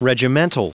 Prononciation du mot : regimental
regimental.wav